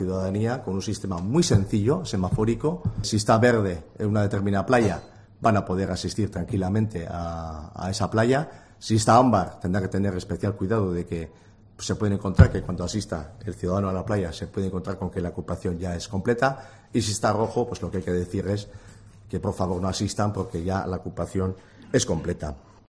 Jose Ignacio Asensio , diputado de Medio Ambiente